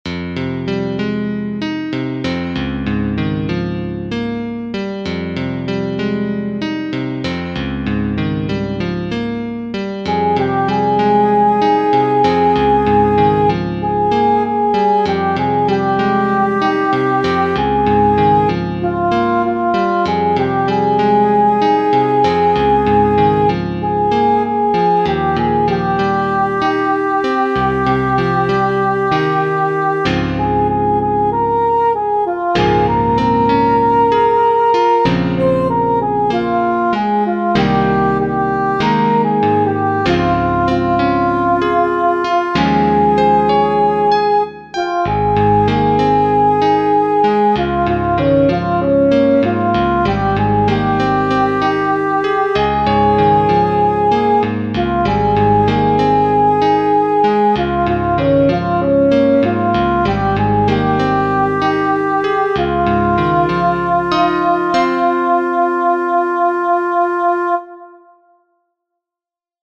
Alto I